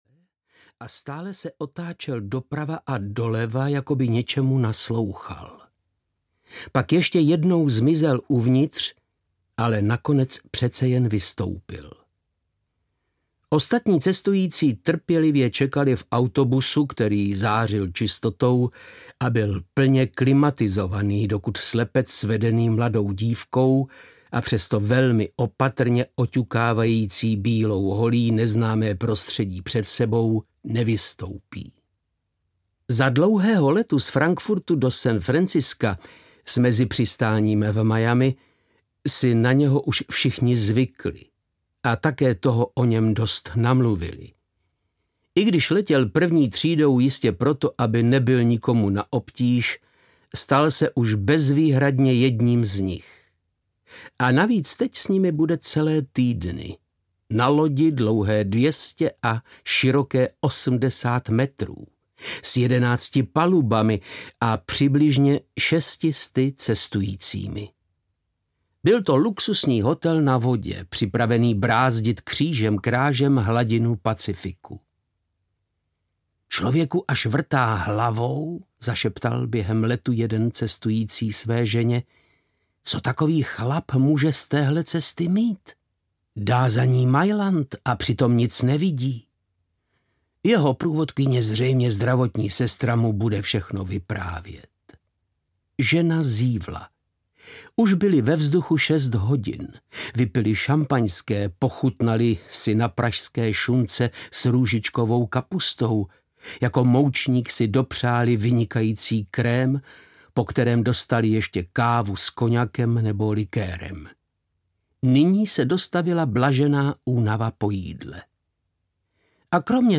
Hříšná plavba Pacifikem audiokniha
Ukázka z knihy
• InterpretVáclav Knop